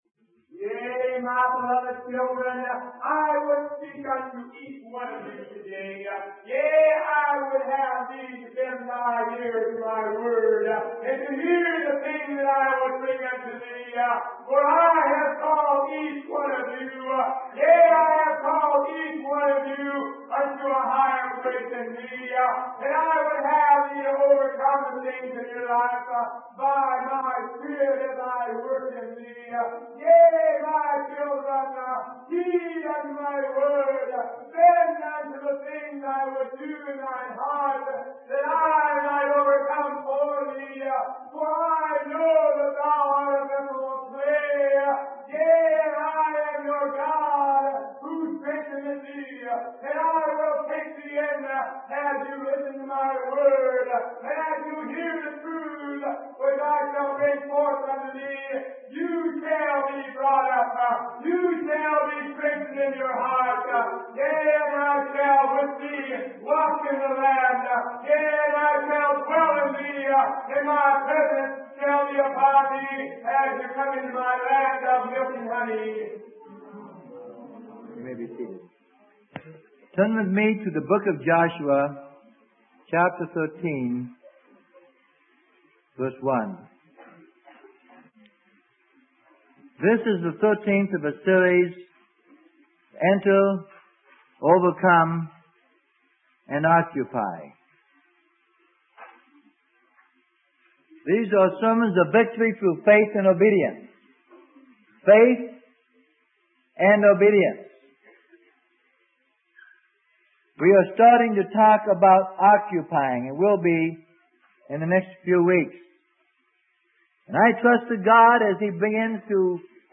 Sermon: Enter Overcome and Occupy - Part 13 - Freely Given Online Library